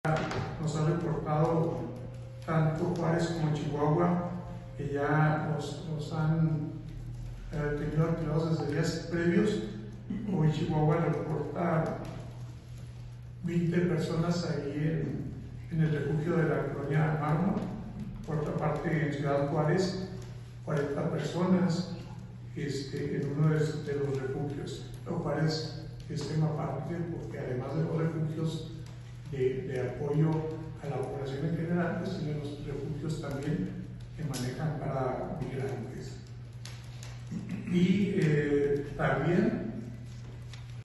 AUDIO: LUIS CORRAL TORRESDEY, TITULAR DE LA COORDINACIÓN ESTATAL DE PROTECCIÓN CIVIL ( CEPC )